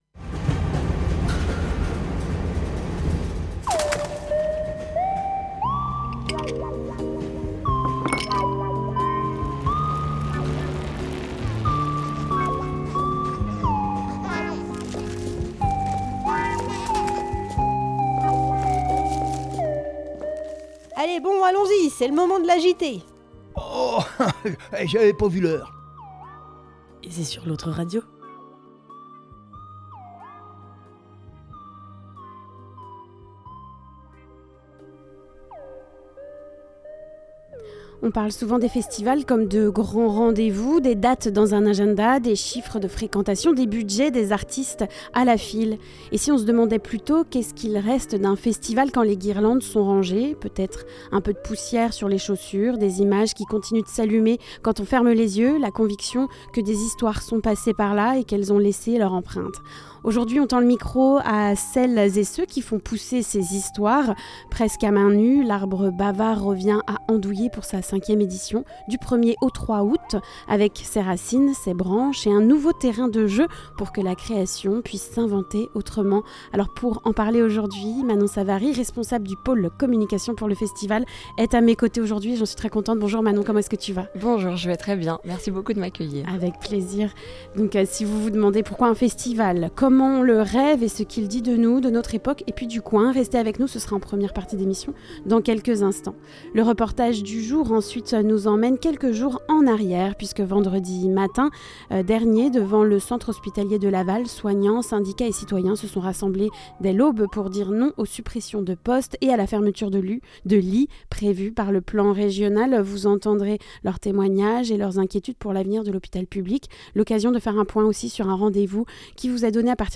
La revue de presse du Haut Anjou Le reportage : Mobilisation pour l’hôpital public Vendredi matin 27 juin, soignants, syndicats et citoyens se sont rassemblés devant le centre hospitalier de Laval pour dire non aux suppressions de postes et de lits.